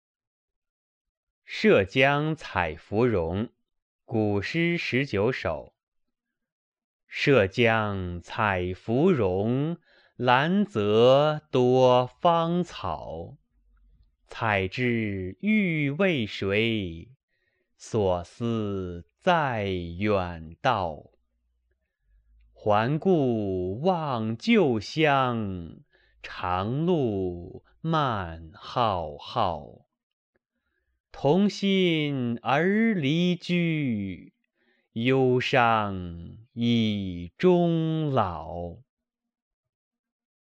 《涉江采芙蓉》原文、译文、赏析（含朗读）　/ 《古诗十九首》